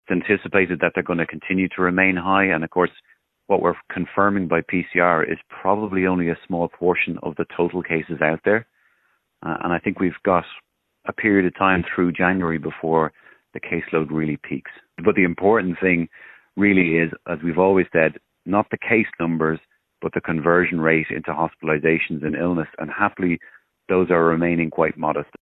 Infectious Diseases Consultant